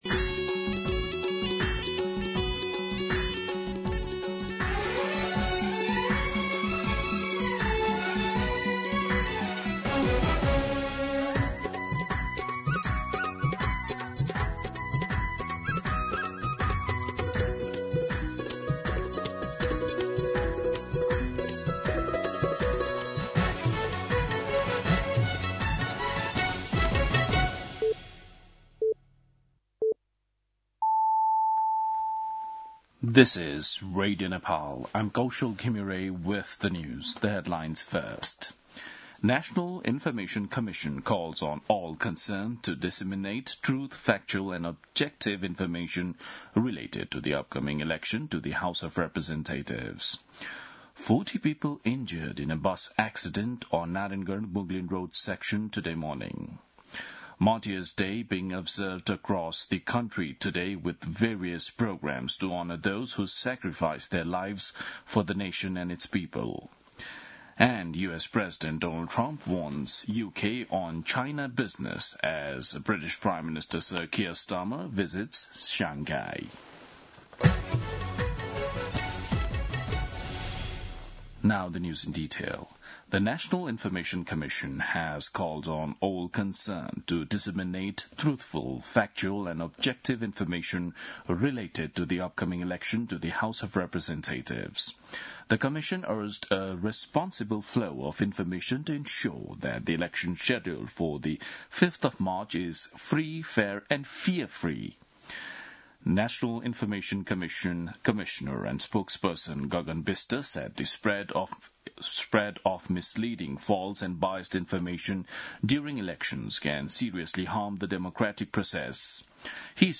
दिउँसो २ बजेको अङ्ग्रेजी समाचार : १६ माघ , २०८२